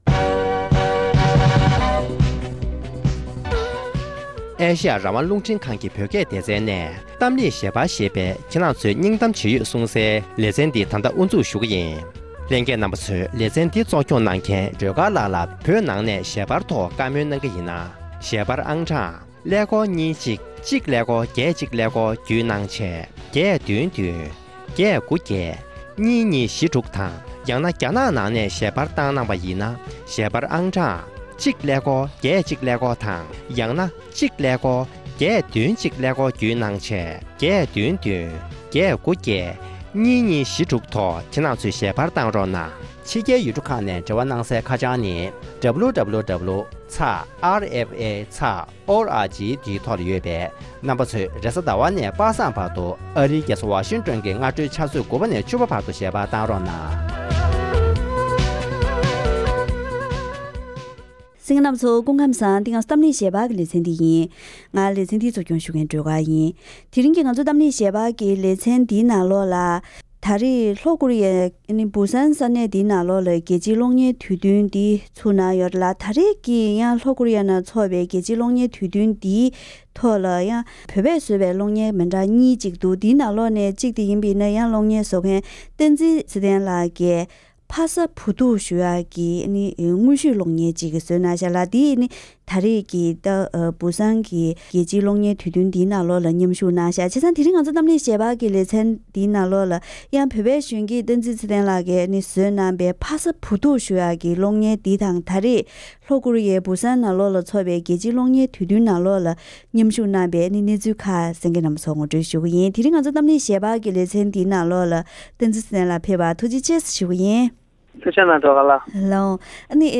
བཀའ་མོལ་ཞུས་པ་ཞིག་ལ་གསན་རོགས།།